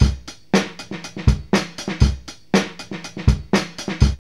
BOUNCY   120.wav